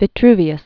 Vi·tru·vi·us
(vĭ-trvē-əs) Full name Marcus Vitruvius Pollio. fl. first century BC.